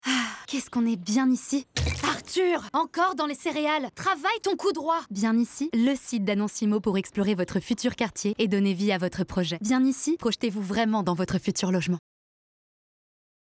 Pub TV - Bien Ici - comédie
Je suis appréciée dans la Pub, les films institutionnels, le jeu vidéo, la narration et ma voix est parfois douce, parfois dynamique et chaleureuse.
5 - 40 ans - Mezzo-soprano